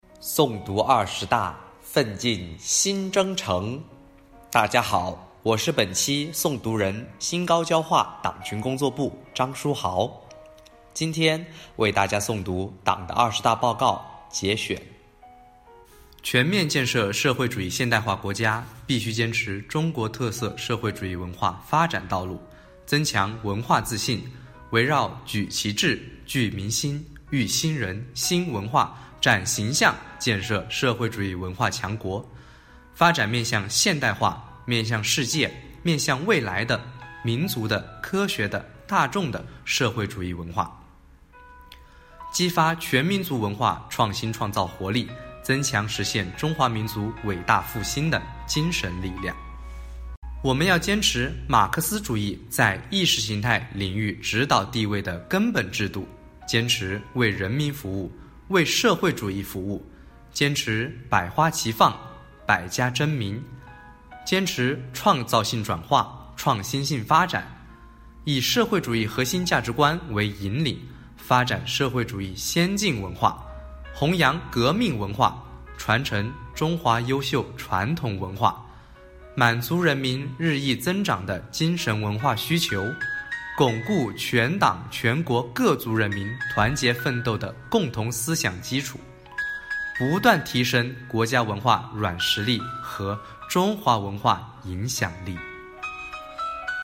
本期诵读